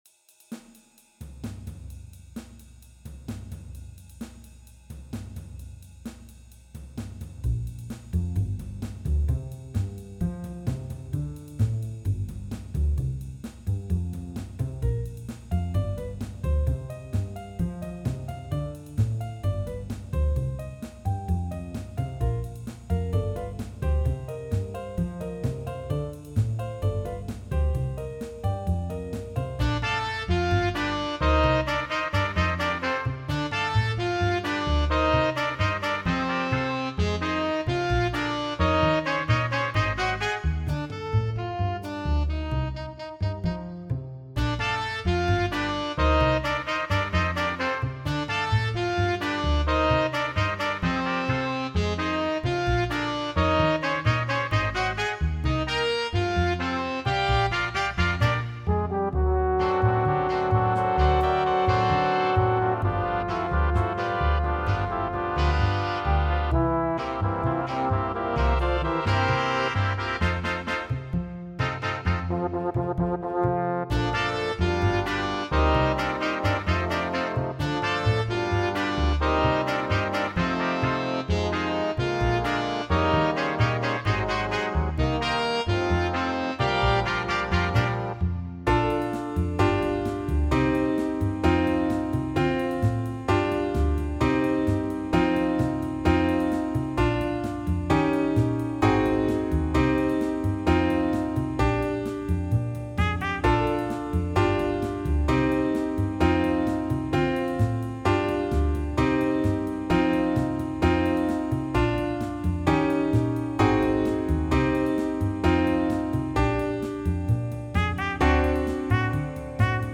Big Band
All audio files are computer-generated.
A fun, bolero style (tempo = 130) with several improvised solo sections (piano, tenor sax, trumpet, bass, drums). Piano part requires comping.